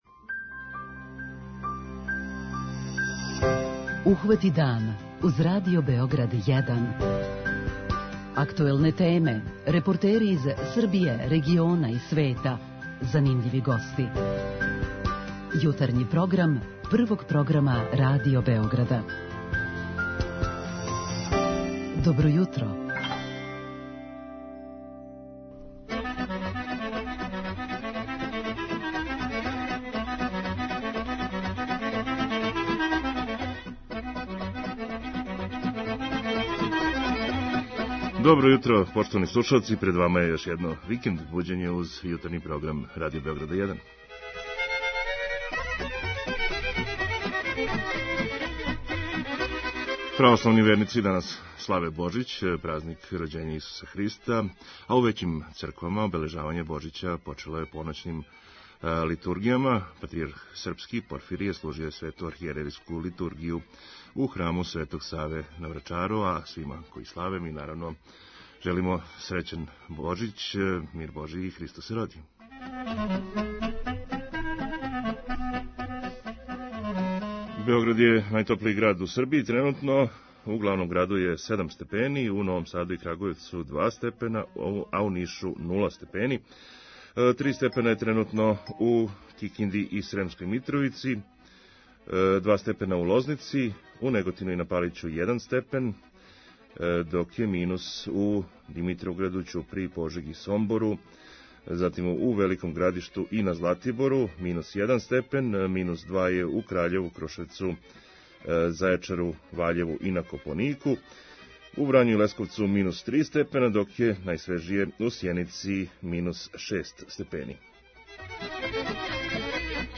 У овом божићном издању нашег јутарњег програма прошетаћемо Србијом, али и регионом, Посетићемо Бањалуку одакле нам колеге из Радија Републике Српске шаљу једну звучну разгледницу поноћног бдења пред Божић у Саборном храму Христа Спаситеља. Проверићемо и како протиче божићно јутро на Косову и Метохији, а о традицији и обичајима на овај дан причаћемо са нашим дописницима из Чачка и Врања.